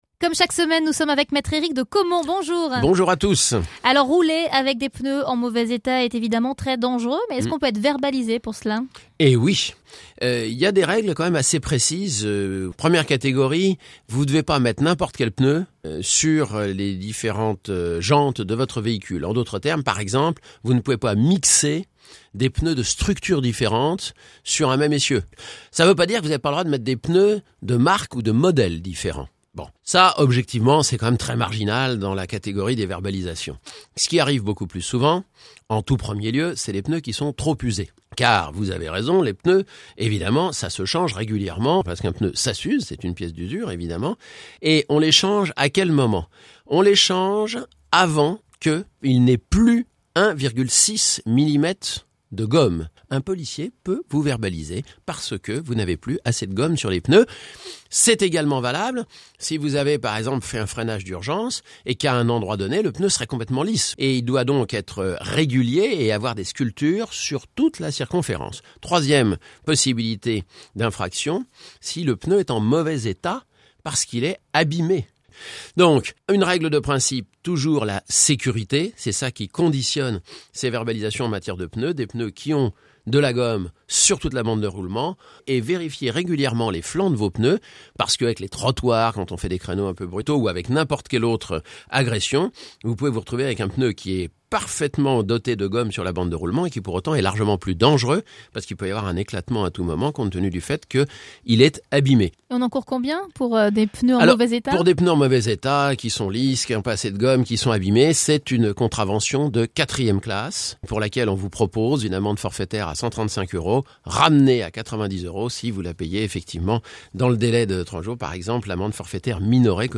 Chronique du 16/12/2012 – Pneus usés ou abimés